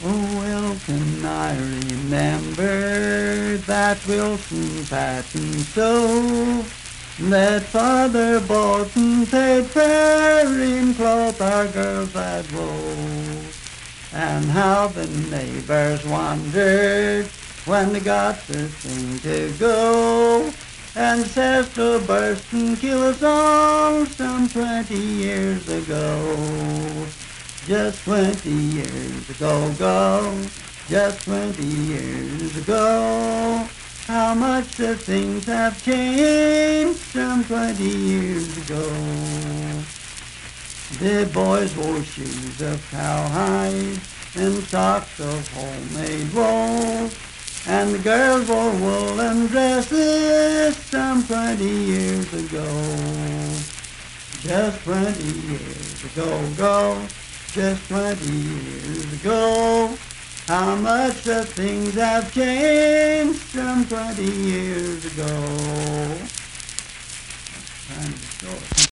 Unaccompanied vocal music
Verse-refrain 2d(4-6w/R).
Performed in Dryfork, Randolph County, WV.
Voice (sung)